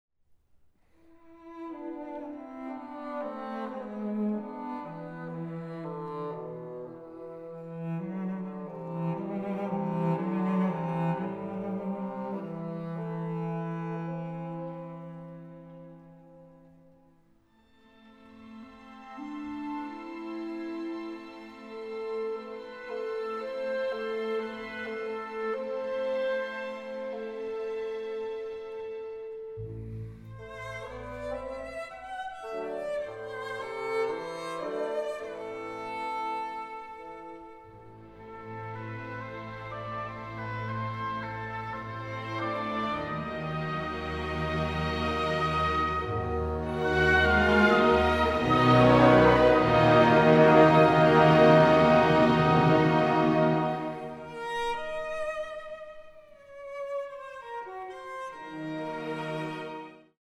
Aufnahme: Rehearsal Hall, Megaron, Athens, 2024